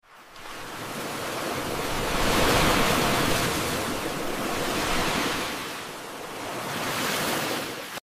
Satisfying ASMR videos of Dollar's/ sound effects free download